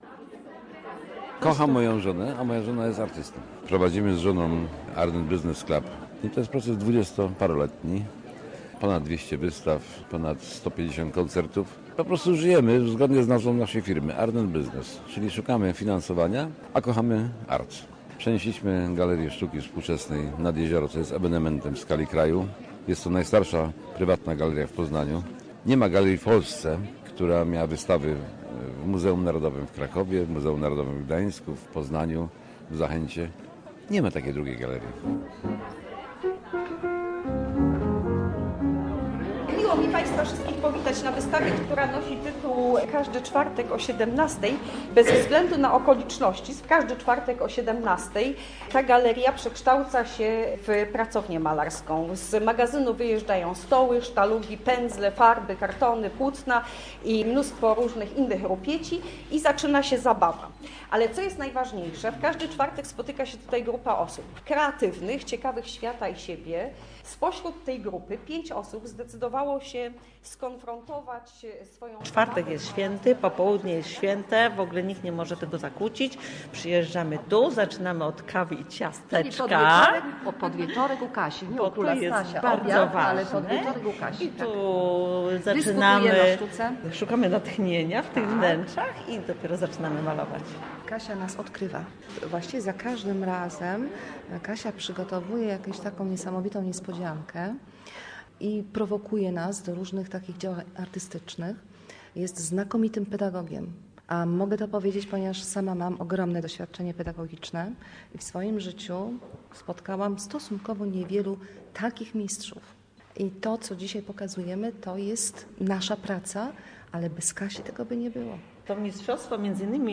Strzeszyńskie czwartki - reportaż